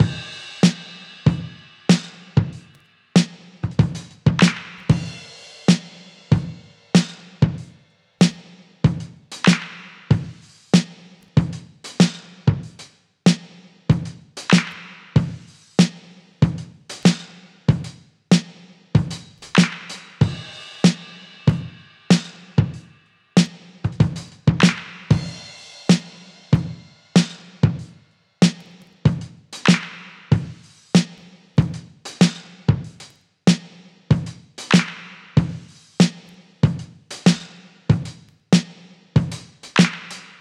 • 95 Bpm Drum Beat E Key.wav
Free breakbeat - kick tuned to the E note. Loudest frequency: 933Hz
95-bpm-drum-beat-e-key-fVo.wav